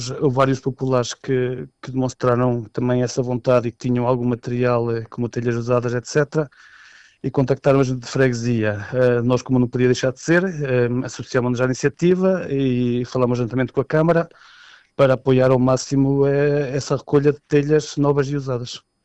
O presidente da Junta de Freguesia de Macedo de Cavaleiros, David Vaz, explica os motivos que levaram a freguesia a associar-se a esta iniciativa solidária: